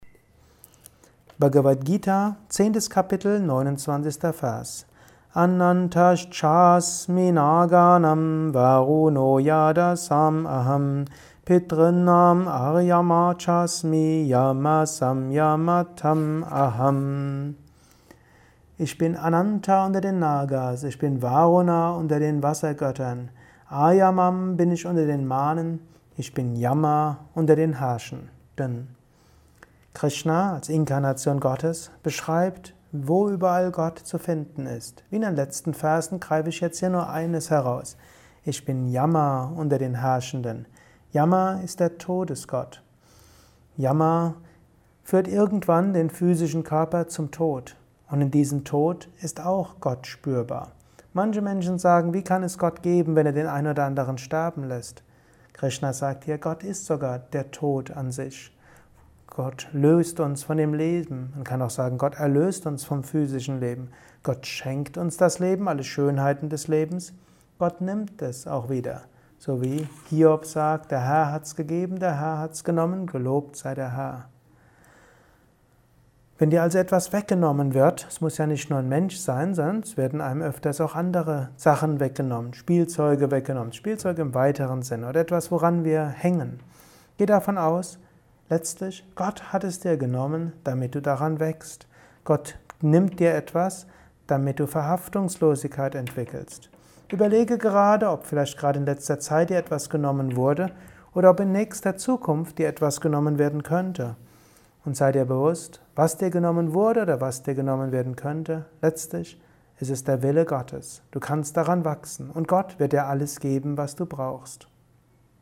Höre einen kurzen Beitrag zur Bhagavad Gita Kapitel X. Vers 29: Gott ist überall zu finden. Dies ist ein kurzer Kommentar als Inspiration für den heutigen Tag